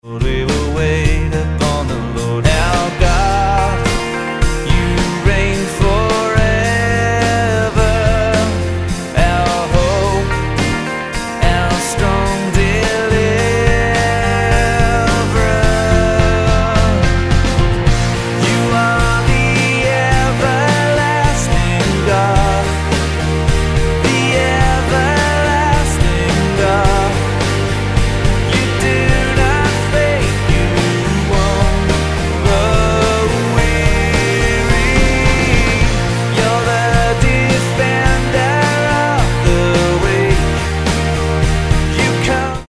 Bb